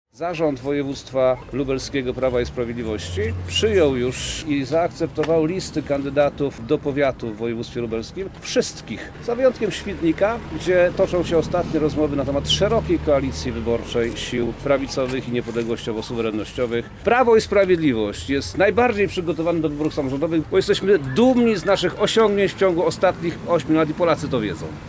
Przemysław Czarnek– mówił poseł Przemysław Czarnek.